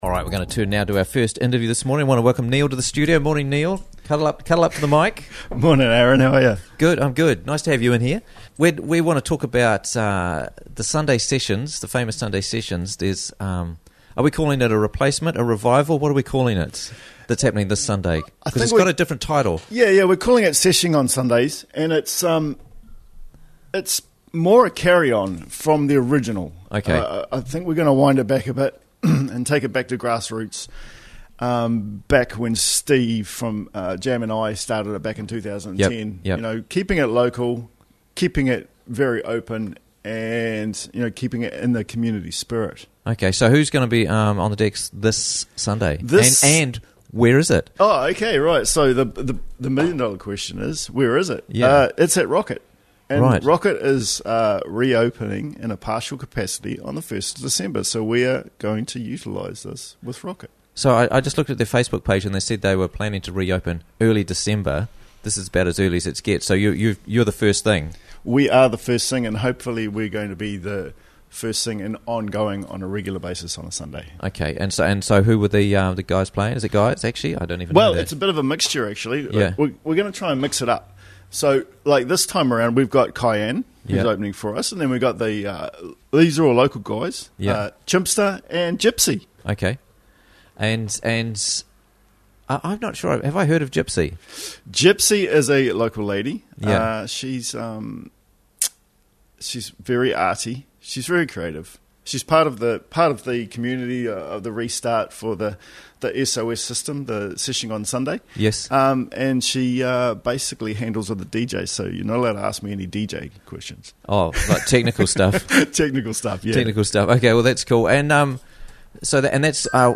Seshing On Sunday - Interviews from the Raglan Morning Show
in-studio